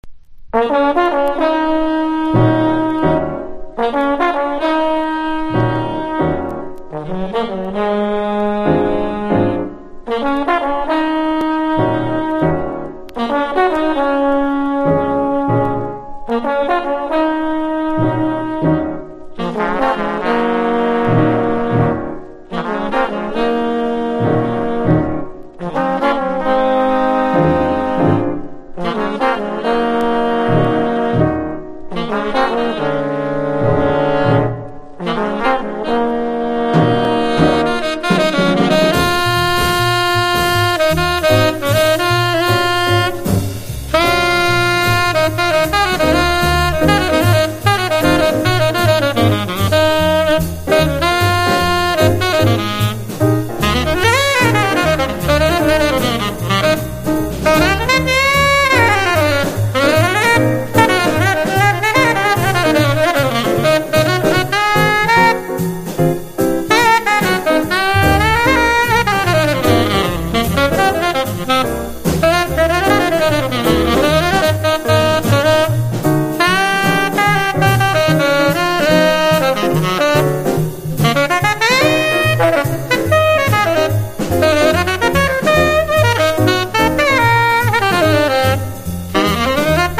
ハードバップ